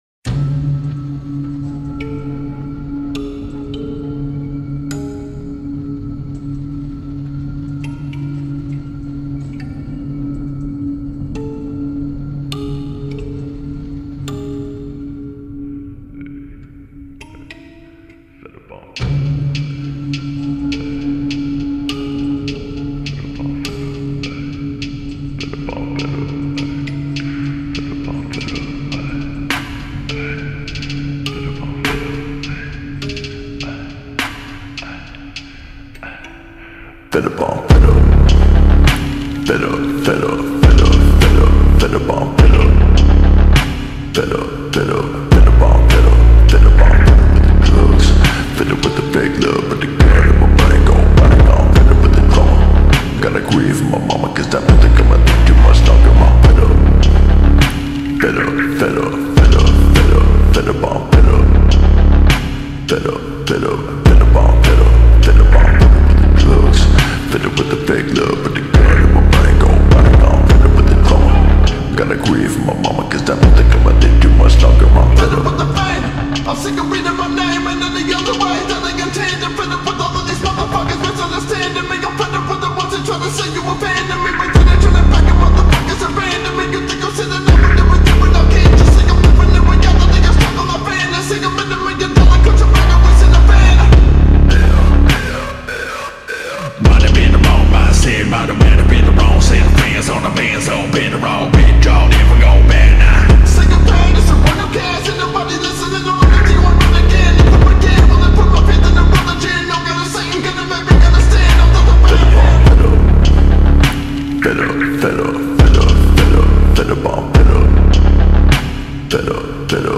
ورژن آهسته